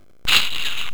horseman_attack5.wav